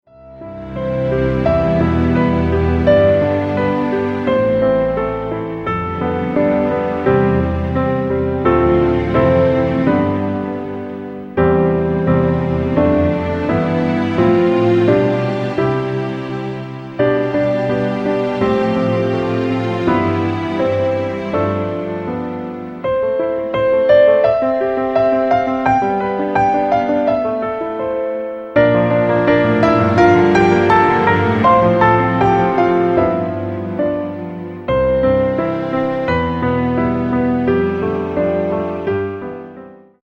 Piano - Strings - Low